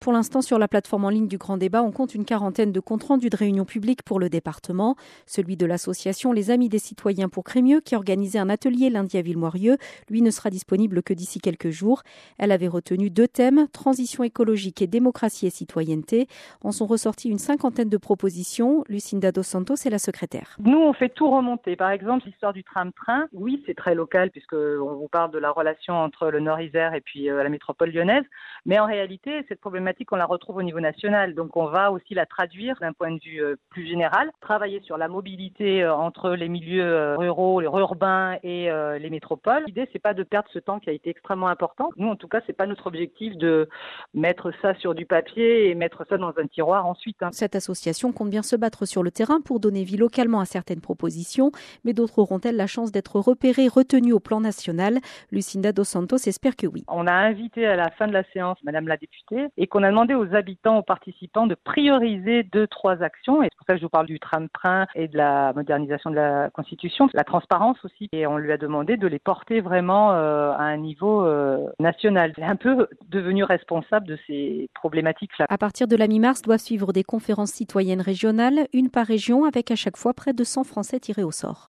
--> Ecouter le reportage de France Bleu Isère (1'22'')